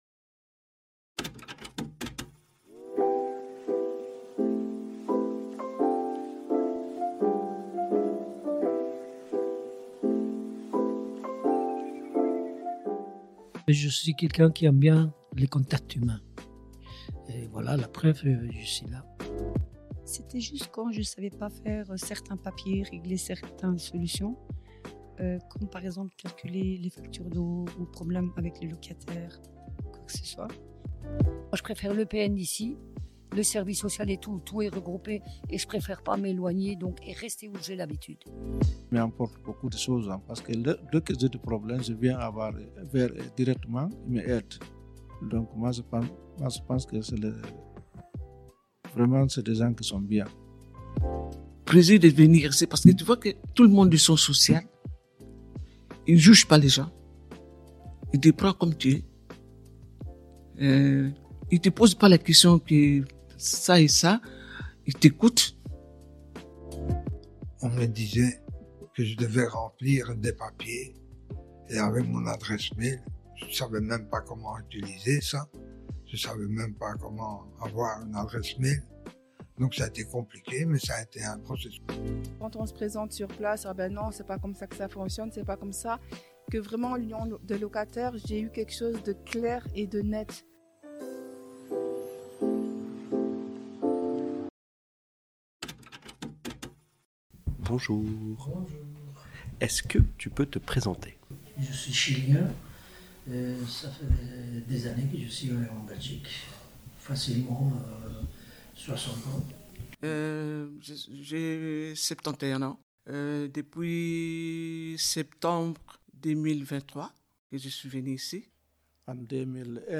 interview
Dans le cadre de notre travail autour de l’inclusion numérique, nous avons réalisé une interview avec quelques habitants qui fréquentent nos services EPN, SSQ ainsi que l’Union des locataires. À travers cet échange, ils partagent leur expérience, les difficultés qu’ils rencontrent face au numérique, mais aussi ce que ces espaces et ces services leur apportent au quotidien.